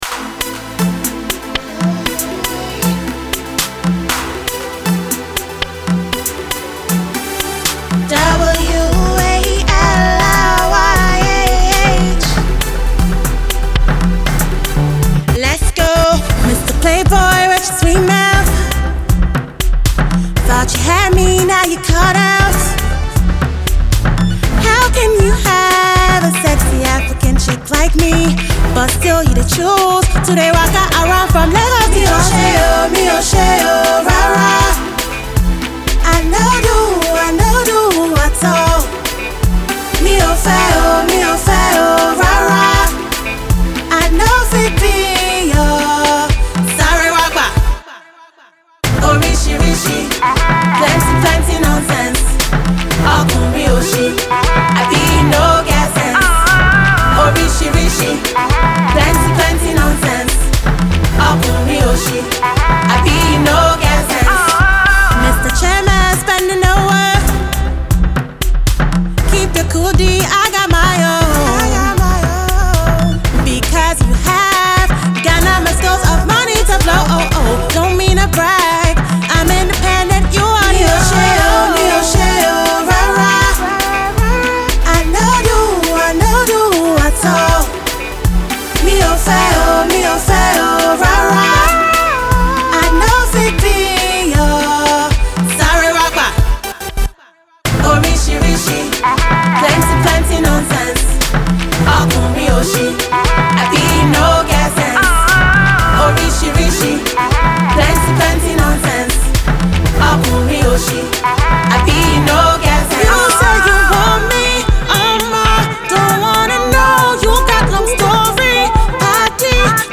Afro-pop
showing off her feisty and sassy side.